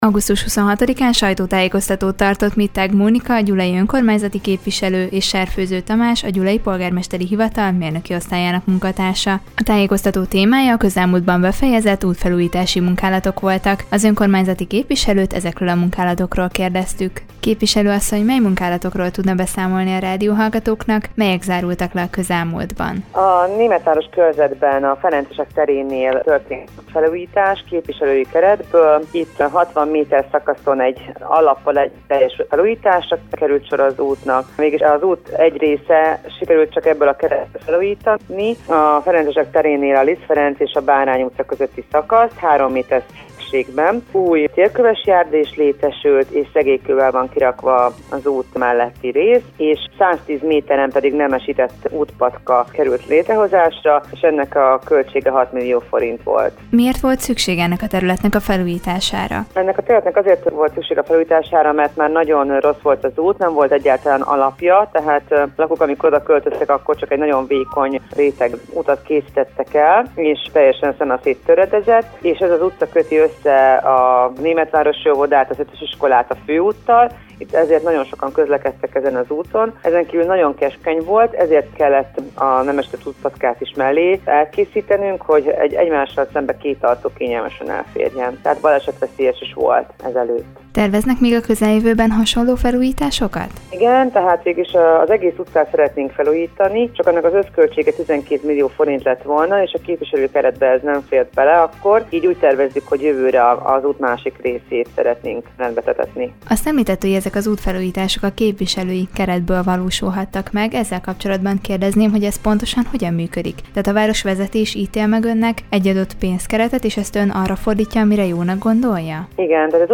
A közelmúltban befejezett útfelújítási munkálatokról kérdeztük Mittág Mónikát, a gyulai önkormányzati képviselőt.